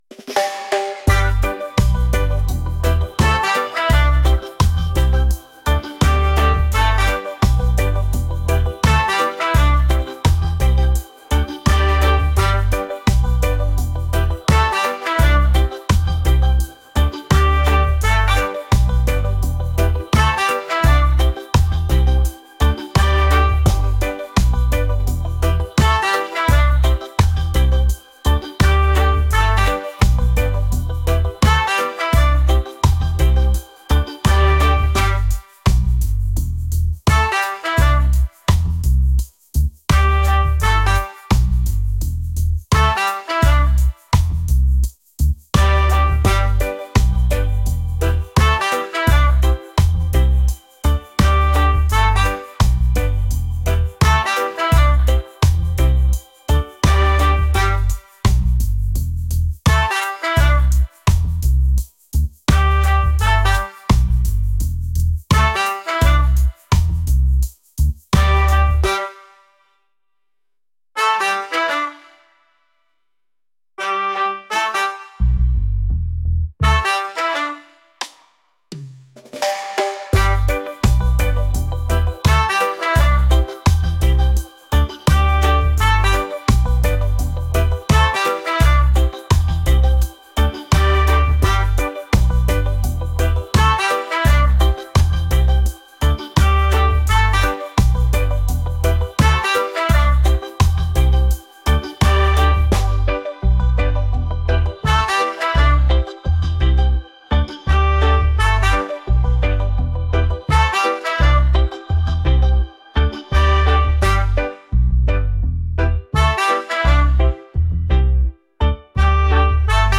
catchy | upbeat | reggae